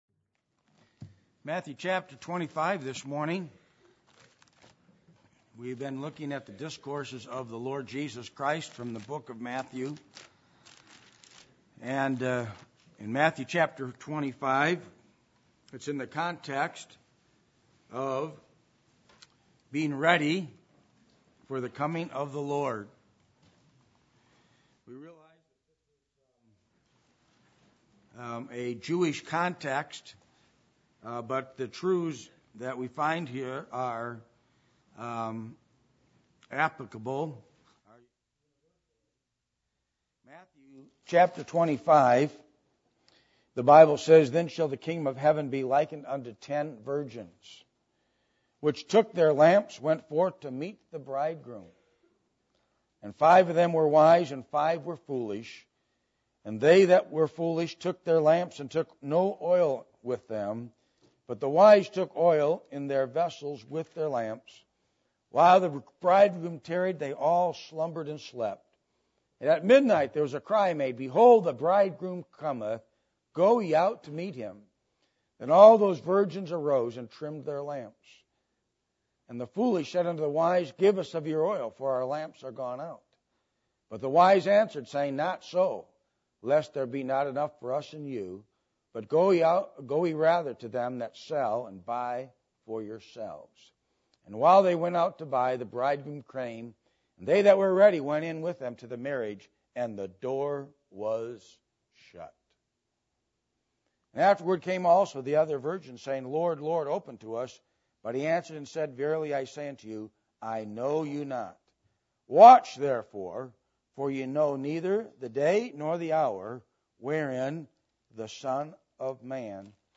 Passage: Matthew 25:1-30 Service Type: Sunday Morning